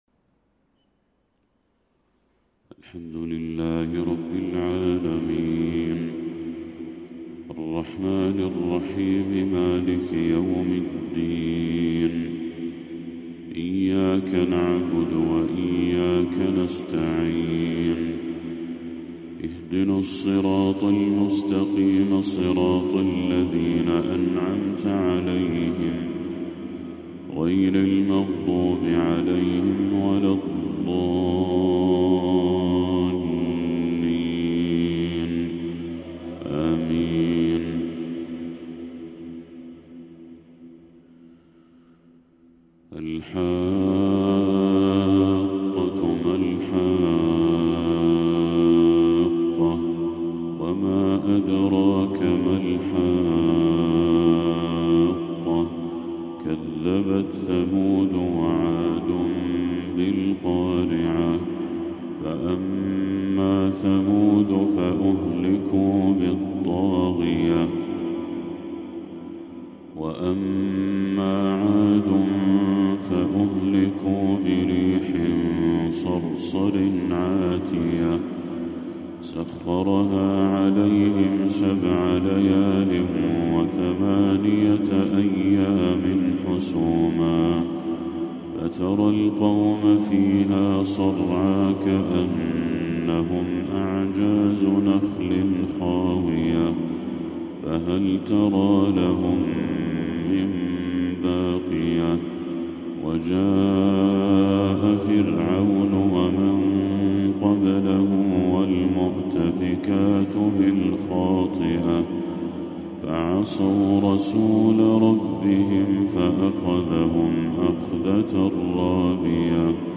تلاوة خاشعة لسورة الحاقة للشيخ بدر التركي | فجر 17 ربيع الأول 1446هـ > 1446هـ > تلاوات الشيخ بدر التركي > المزيد - تلاوات الحرمين